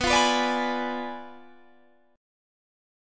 Listen to Bdim7 strummed